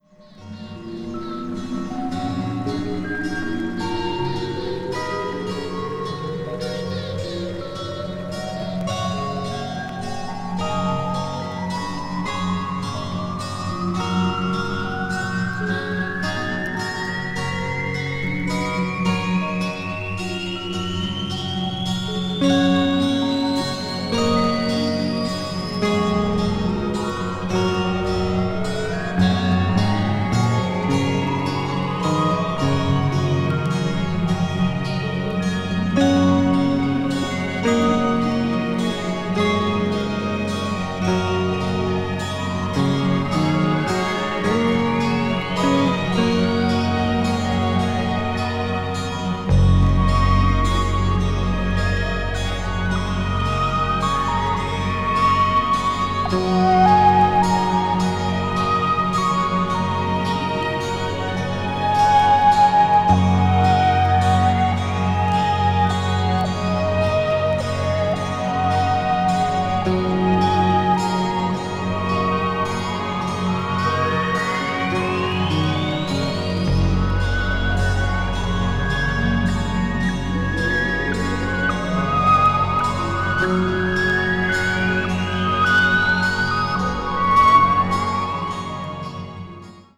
media : EX/EX(わずかにチリノイズが入る箇所あり)
ambient   electronic   meditation   new age   synthesizer